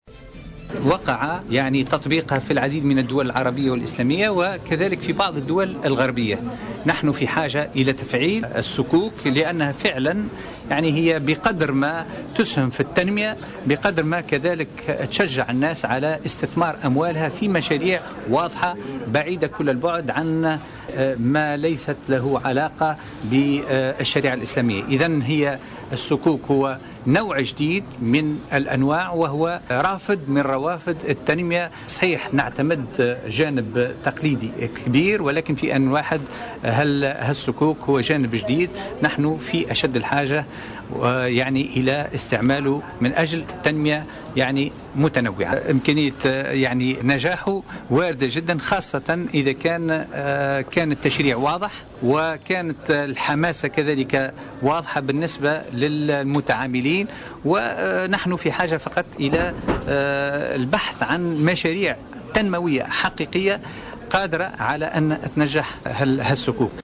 يتواصل اليوم الملتقى الدولي الثالث للمالية الإسلامية في صفاقس تحت عنوان الصكوك ودورها في التنمية الاقتصادية والاجتماعية وكان قد افتتحها وزير الشؤون الدينية منير التليلي الذي بيّن أهمية الصكوك في المساهمة في التنمية والمساعدة على الاستثمار حسب ما صرح به لمراسلنا في الجهة .